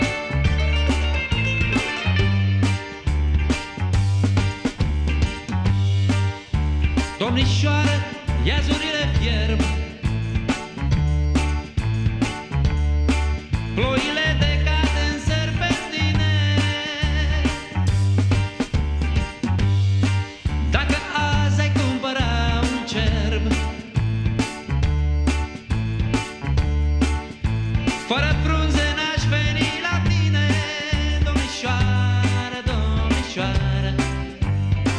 # Фолк-рок